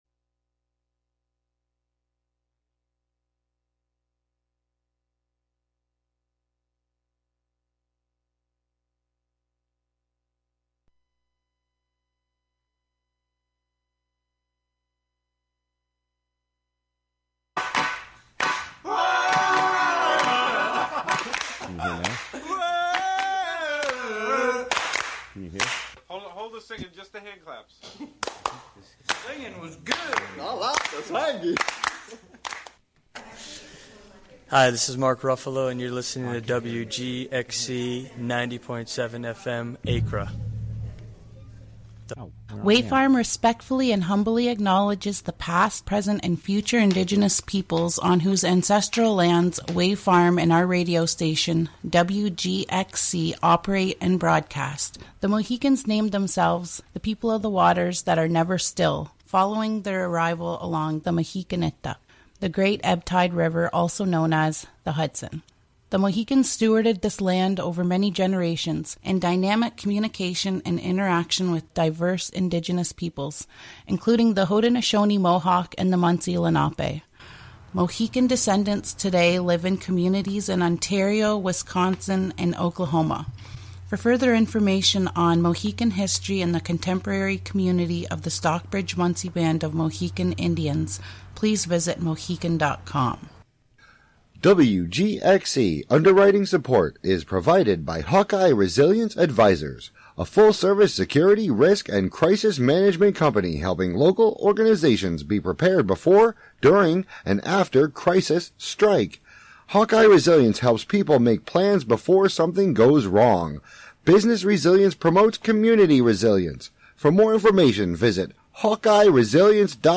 7pm Monthly program featuring music and interviews fro... Monthly program featuring music and interviews from Dutchess County resident broadcast live from WGXC's Hudson studio.